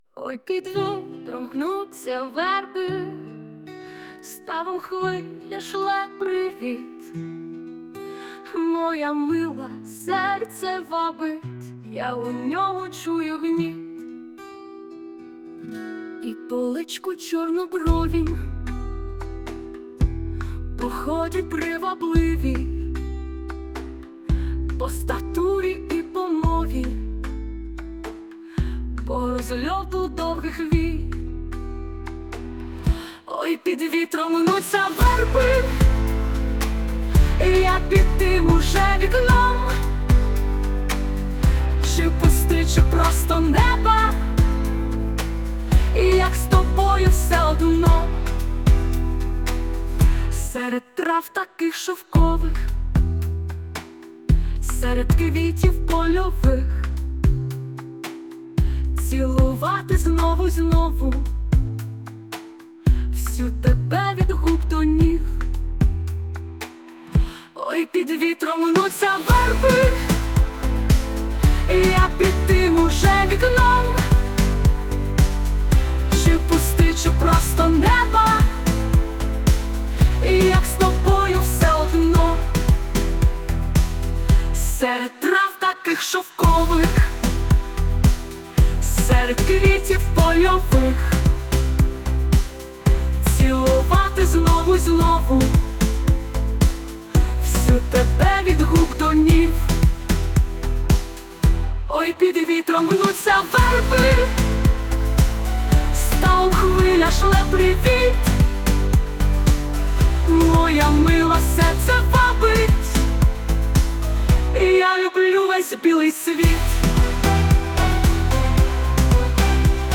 Мелодія на слова пісні:
СТИЛЬОВІ ЖАНРИ: Ліричний